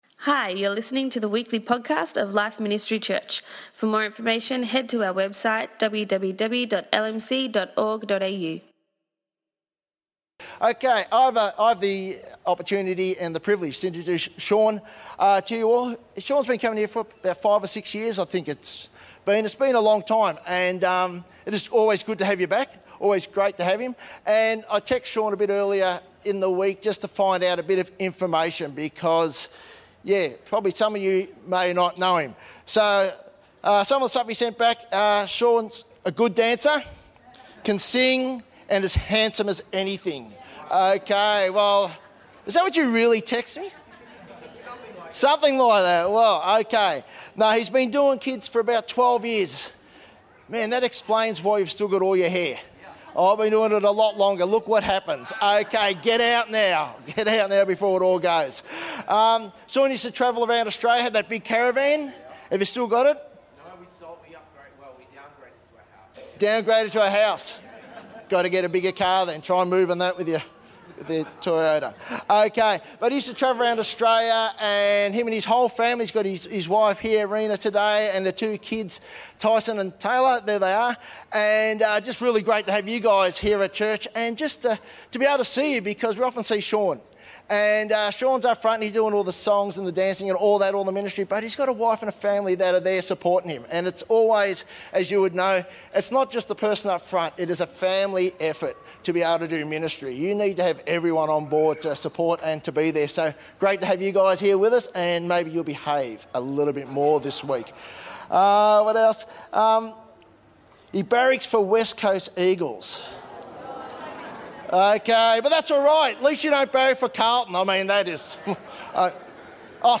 Family Fun Day 2022
an encouraging and interactive message for the whole family